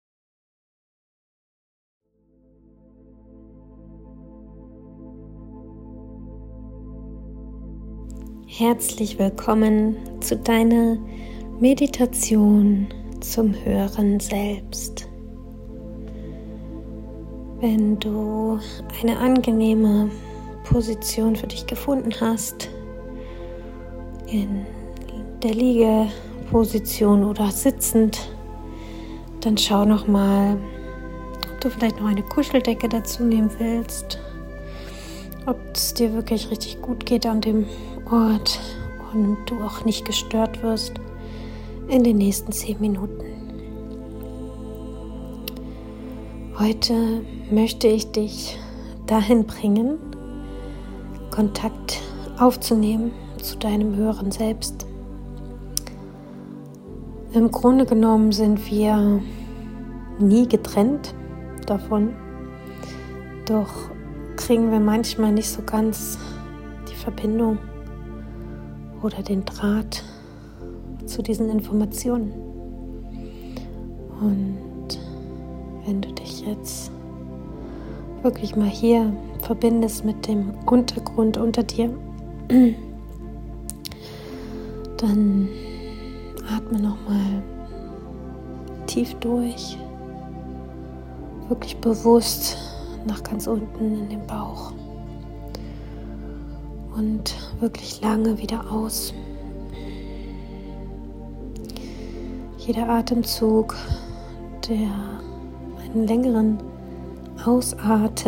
Diese angeleitete Meditation wurde von mir zu Musik in 432Hz-Frequenz aufgesprochen, um dich in die Verbindung mit der besten Version von dir zu bringen.
Meditation-Demo-Hoeheres-Selbst.m4a